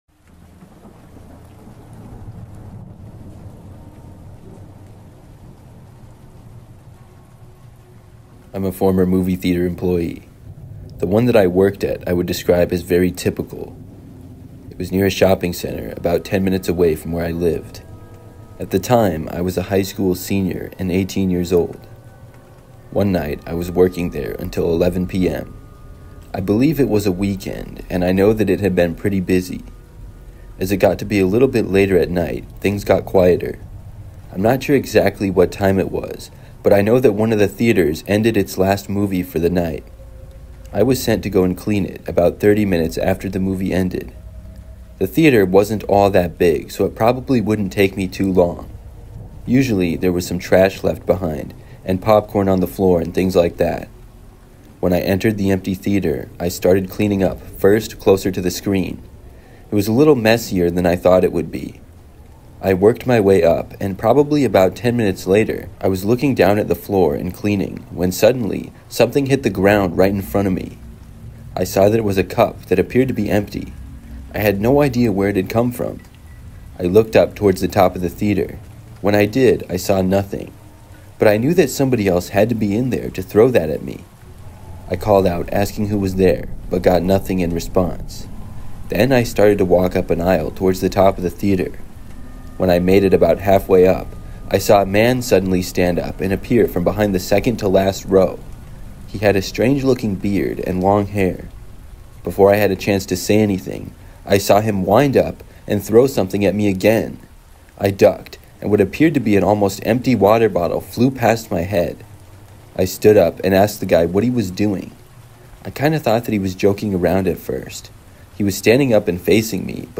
5 True School-Lockdown Stories Told in the Rain